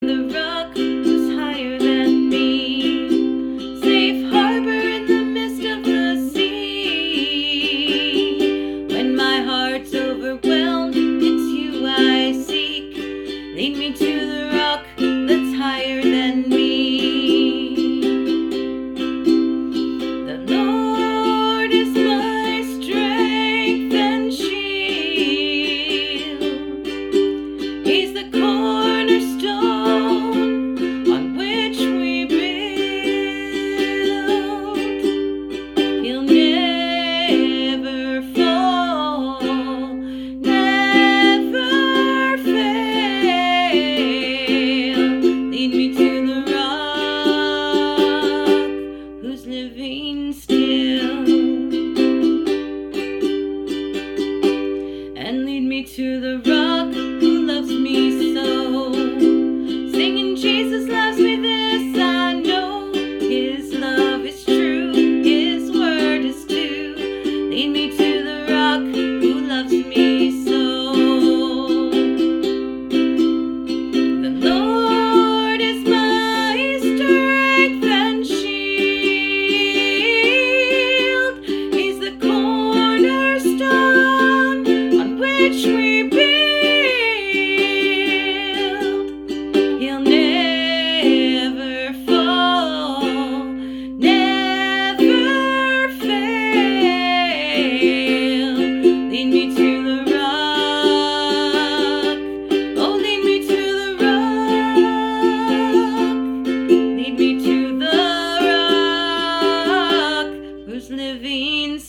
Sweet, reverent song
Love the audio and the ukulele but most of all I love the message.